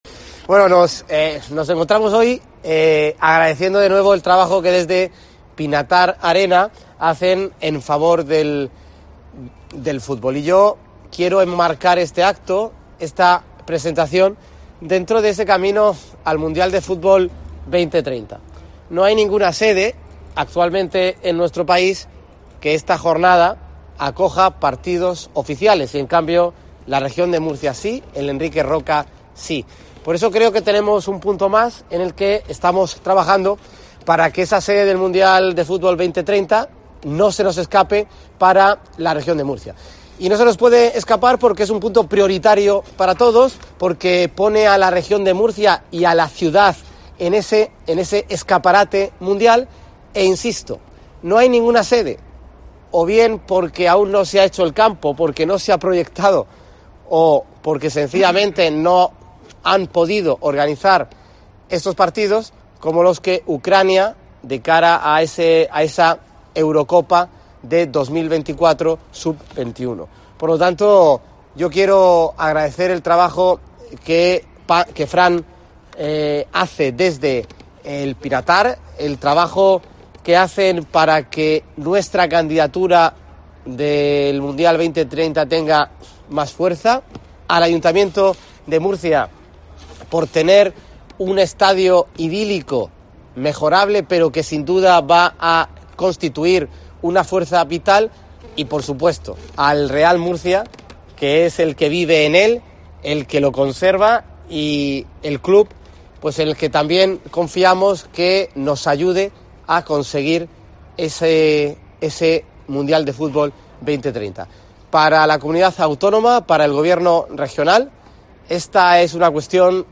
Francisco Javier Sánchez, director general de Deportes